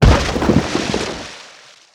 burrow_out_water.wav